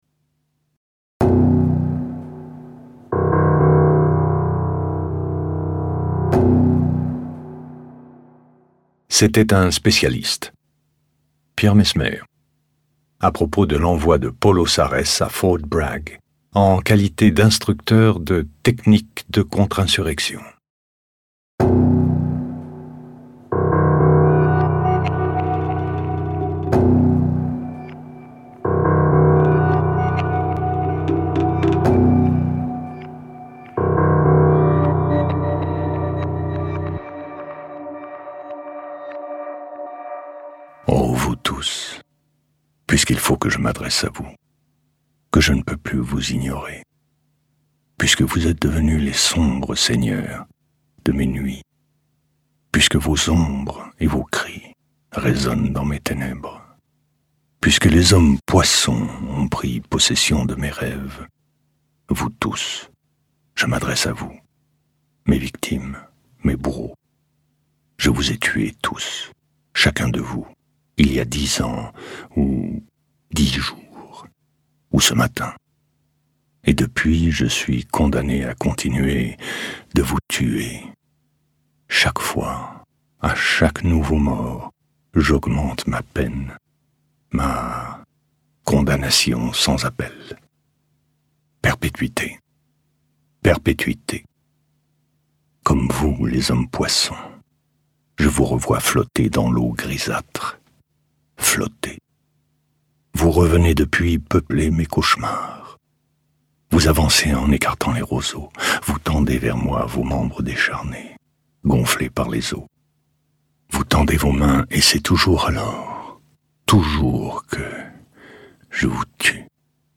Prix du livre audio de la Plume De Paon 2023 dans la catégorie Comptemporain